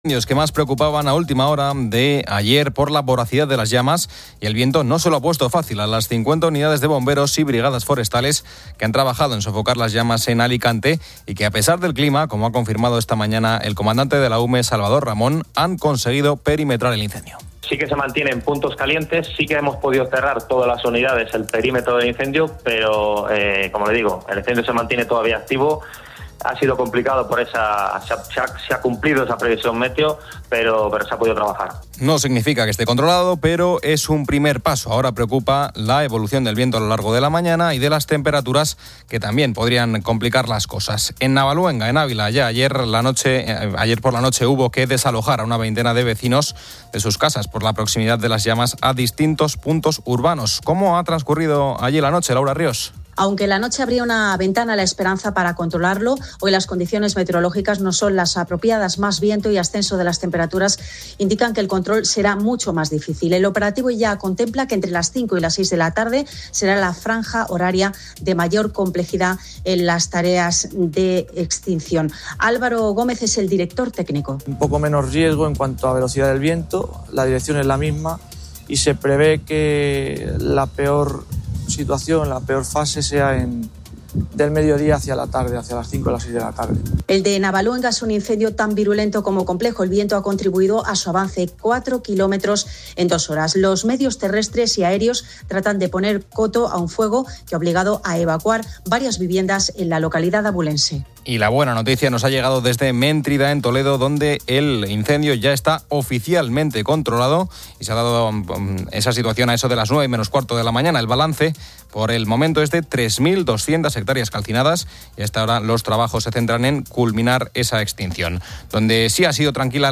Fin de Semana 10:00H | 19 JUL 2025 | Fin de Semana Editorial de Cristina López Schlichting.